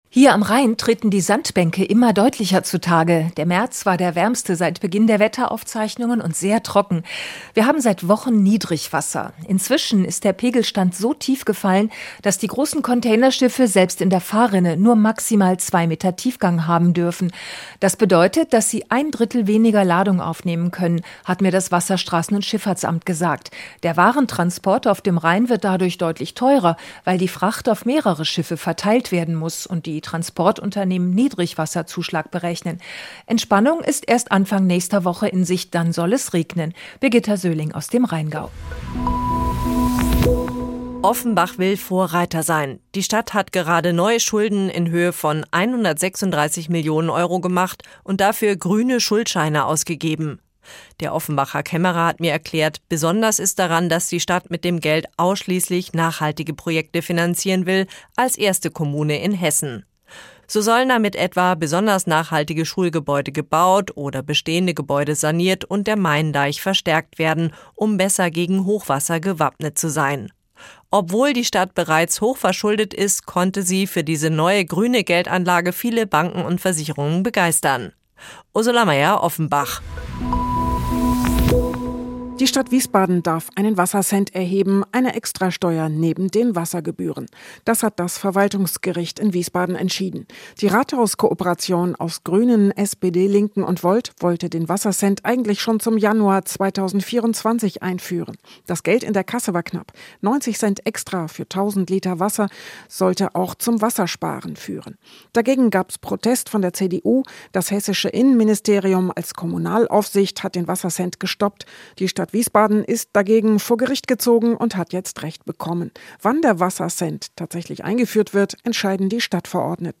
Mittags eine aktuelle Reportage des Studios Frankfurt für die Region